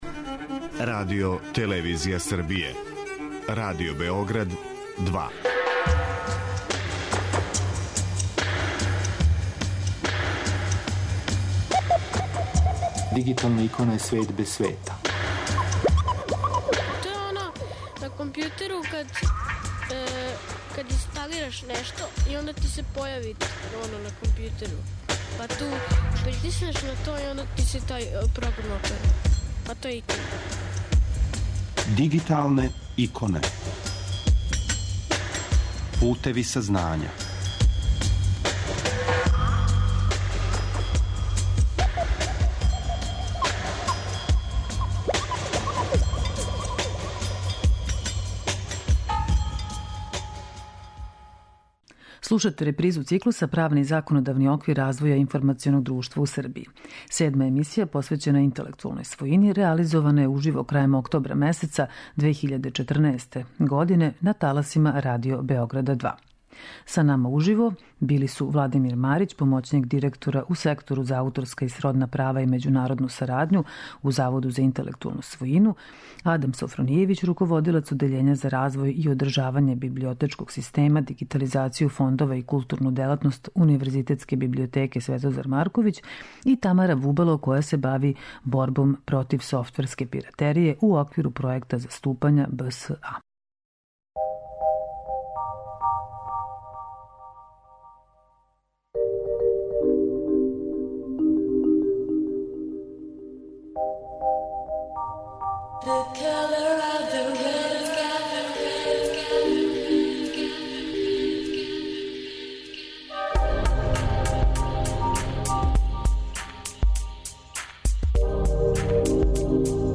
Седма емисија, посвећена интелектуалној својини, реализована је уживо крајем октобра месеца 2014. на таласима Pадио Београда 2.